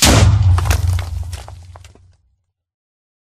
explode3.ogg